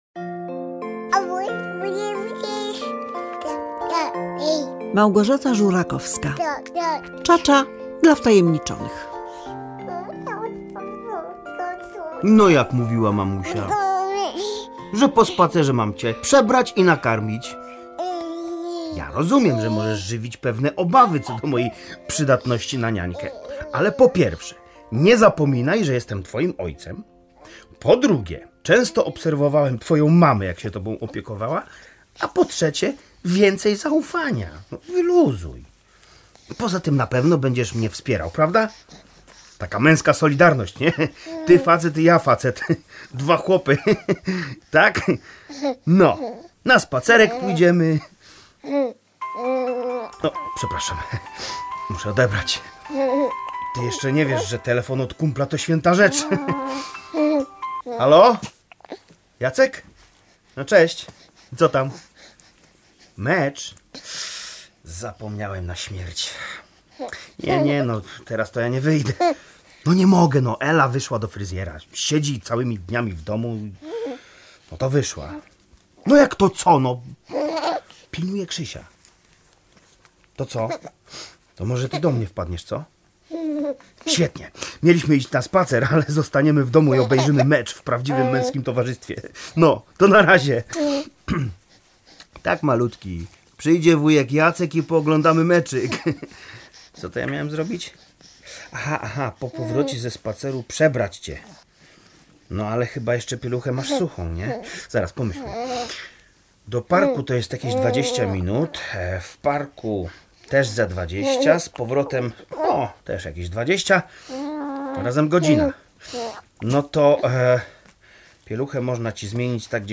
Zaś „Biuro podróży” to słuchowisko o młodym mężczyźnie, który odwiedza biuro podróży, chcąc wykupić podróż poślubną, niestety, zaczynają się piętrzyć pewne trudności.